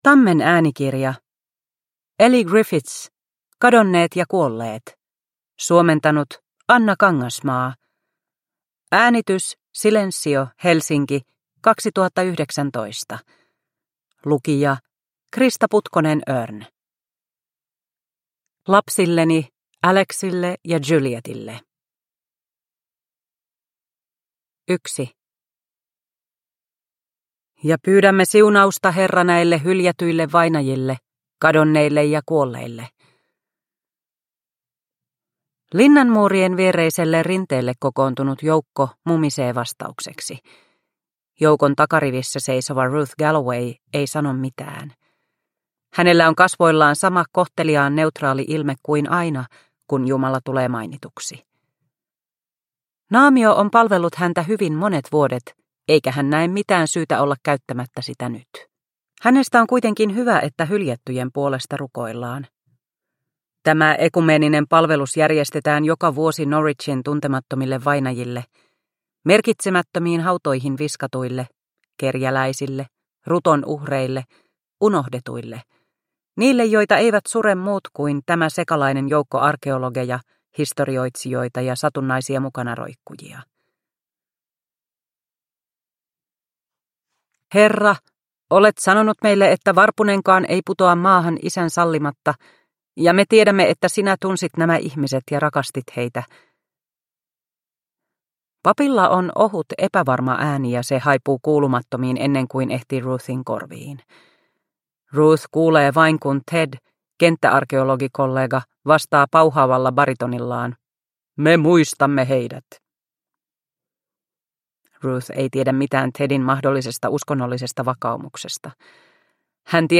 Kadonneet ja kuolleet – Ljudbok – Laddas ner